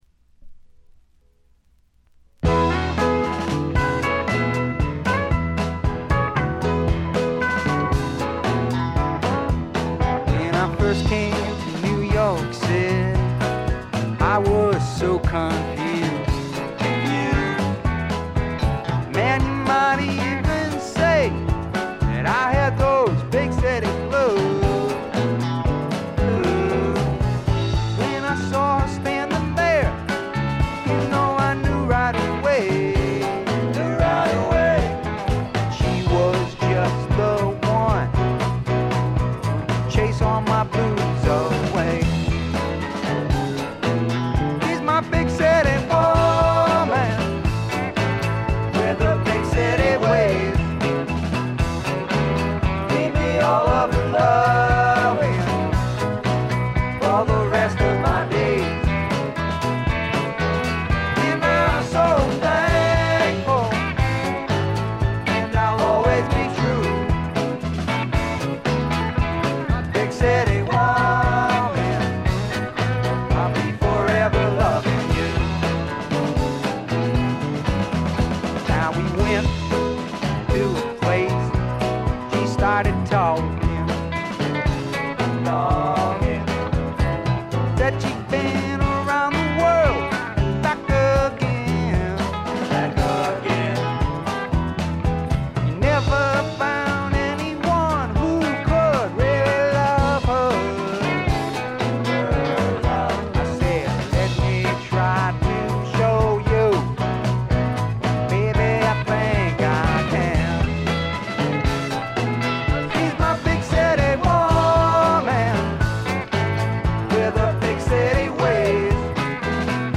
ほとんどノイズ感無し。
スワンプ系シンガーソングライター基本。
試聴曲は現品からの取り込み音源です。
Recorded at Muscle Shoals Sound Studios, Muscle Shoals, Ala.